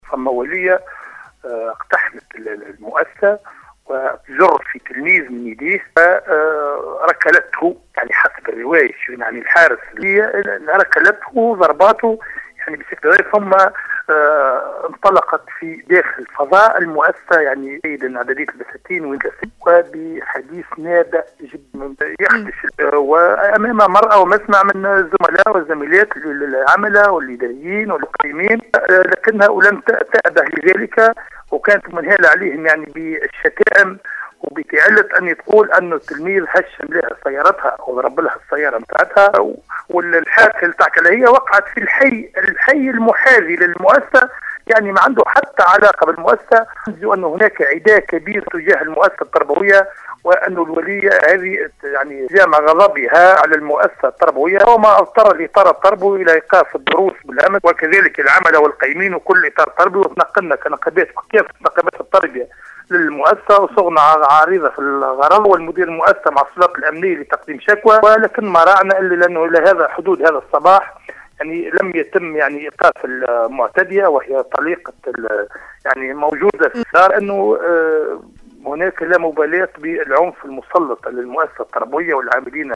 في تدخل هاتفي في برنامج نهارك زين